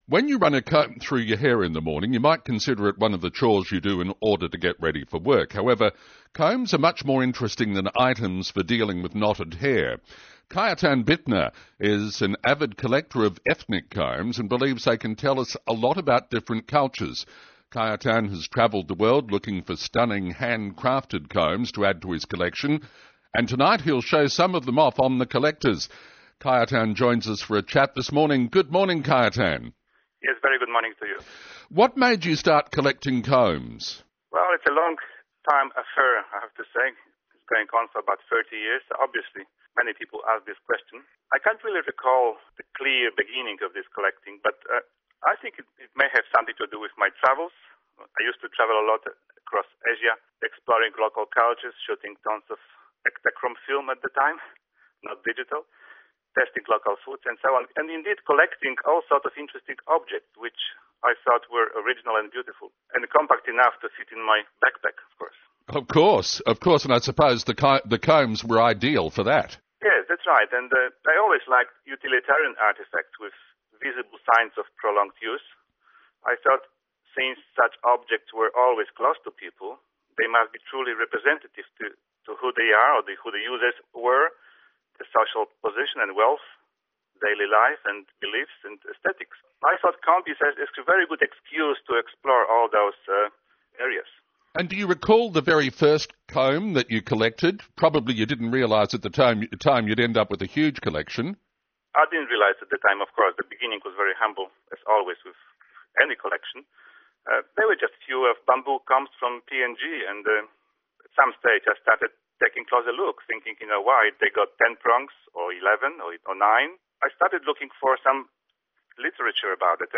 radio interview.